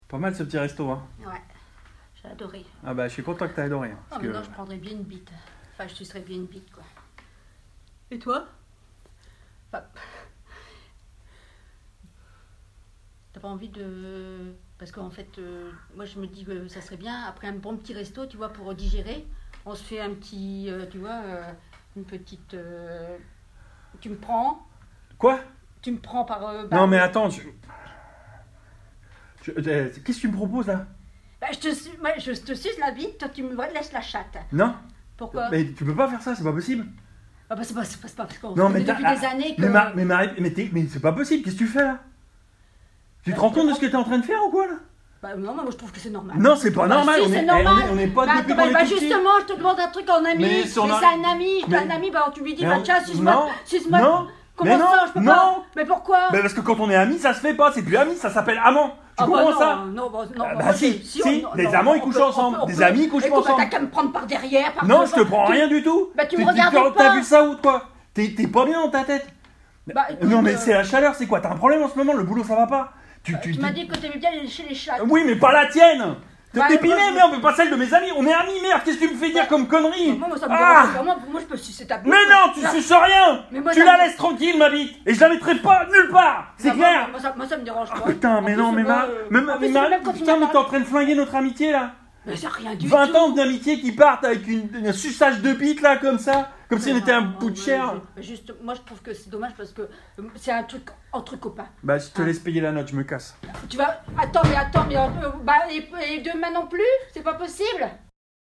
Des fragments de "théâtre improvisé" immatériels, basés sur les relations textuelles.